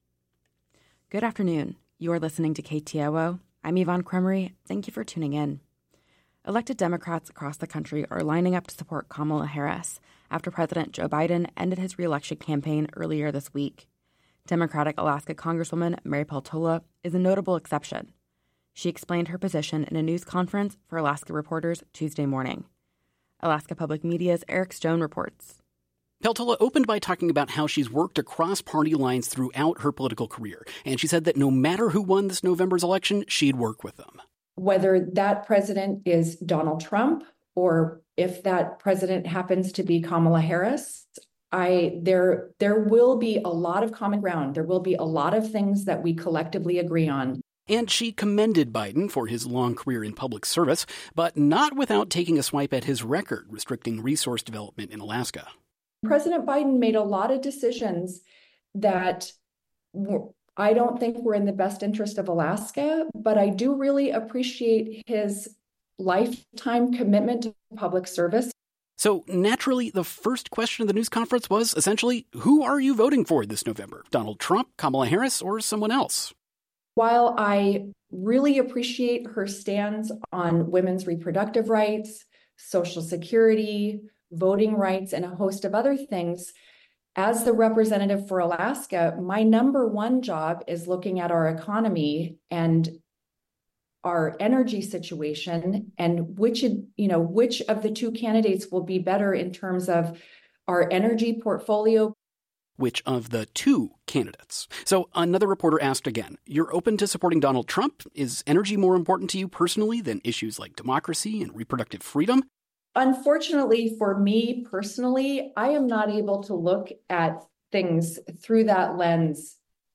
Newscast – Thursday, July 25, 2024